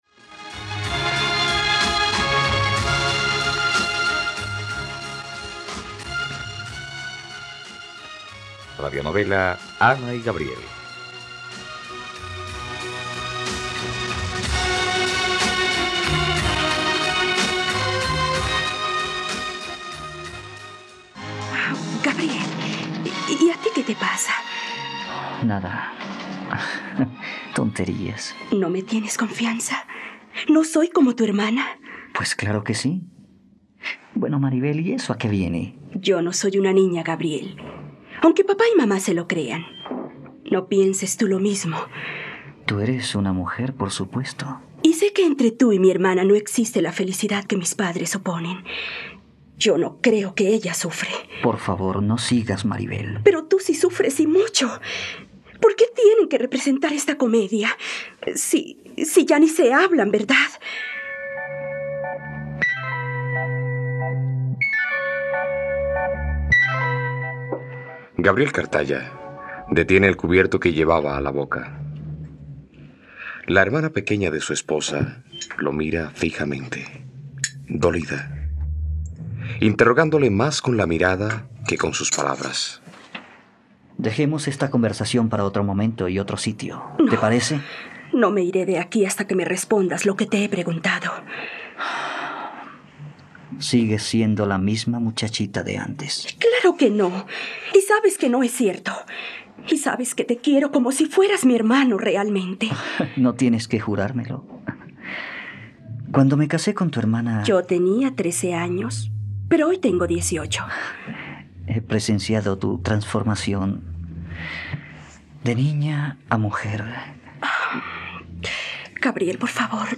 Ana y Gabriel - Radionovela, capítulo 6 | RTVCPlay